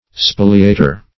spoliator - definition of spoliator - synonyms, pronunciation, spelling from Free Dictionary